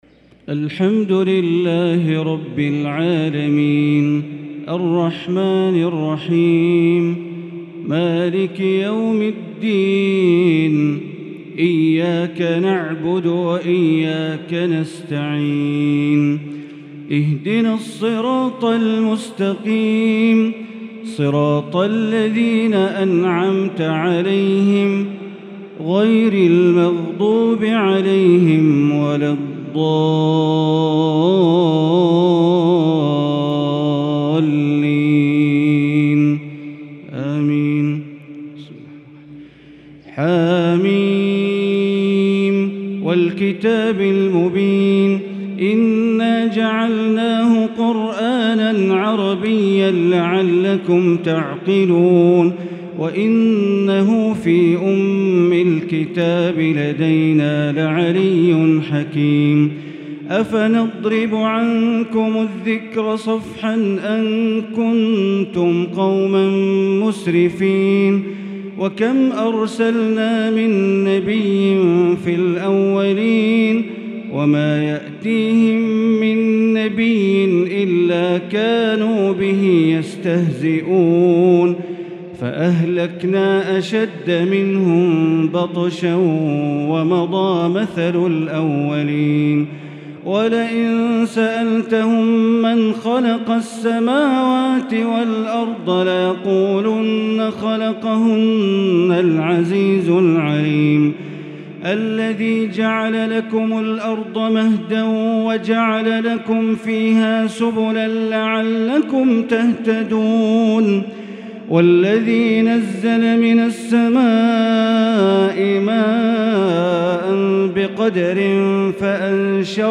صلاة التهجد ليلة 26 رمضان 1443هـ سورة الزخرف كاملة | Tahajjud 26 st night Ramadan 1443H Surah Az-Zukhruf > تراويح الحرم المكي عام 1443 🕋 > التراويح - تلاوات الحرمين